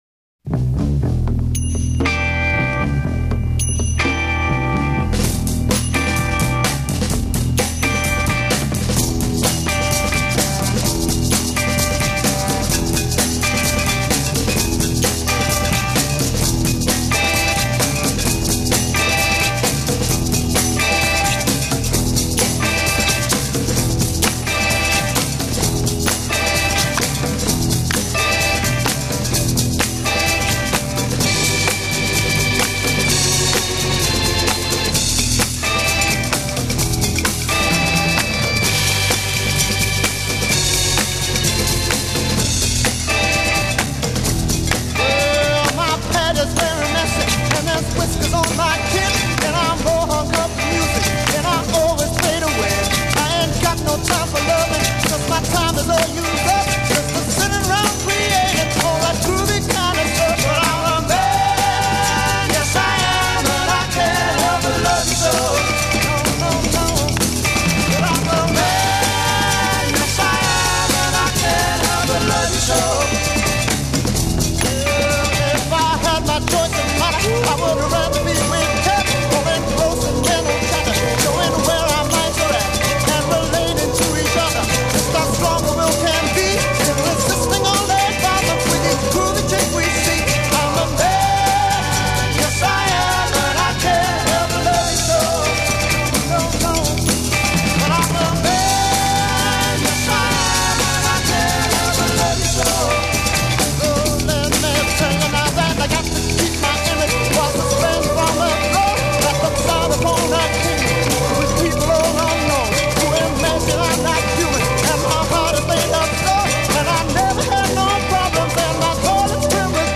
A Verse 0:46 8 Solo voice over backing track. a
Add falsetto backup. c
A Coda 2:13 16+ Responsorial exeunt. b'
British Blues